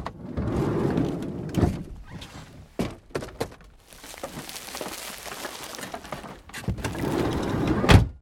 wreck_van_0.ogg